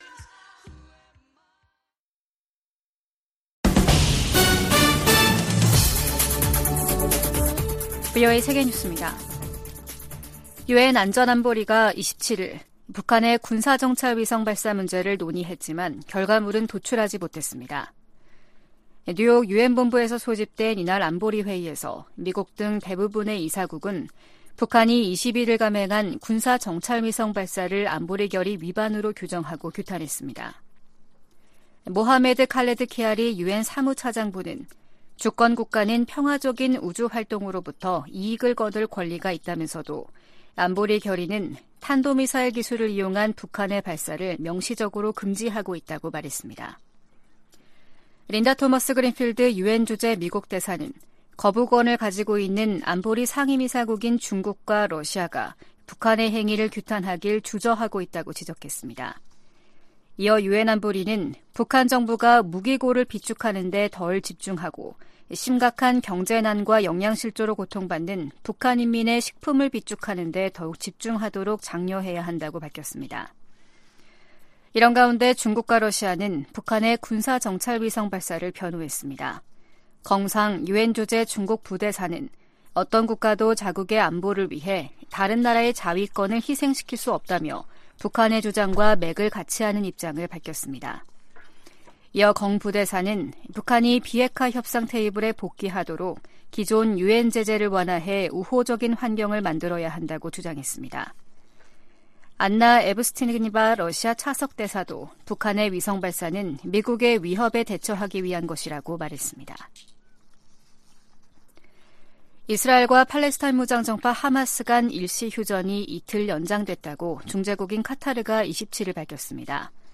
VOA 한국어 아침 뉴스 프로그램 '워싱턴 뉴스 광장' 2023년 11월 28일 방송입니다.